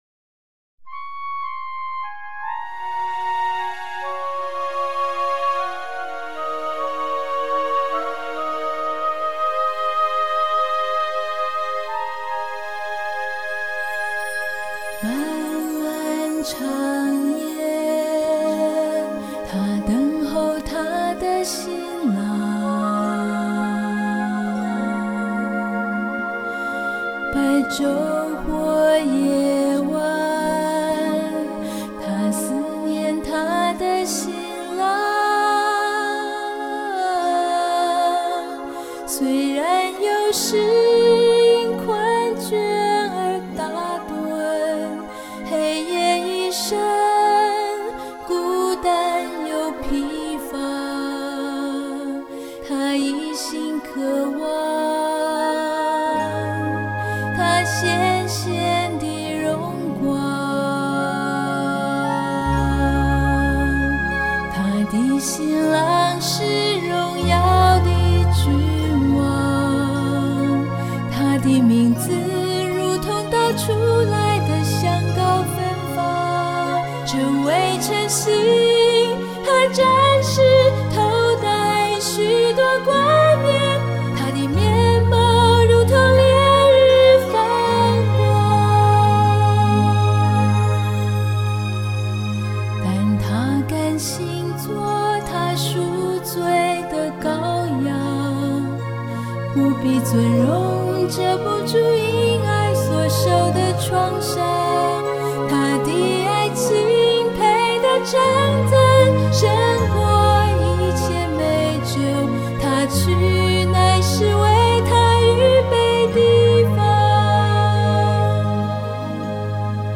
mp3 原唱音樂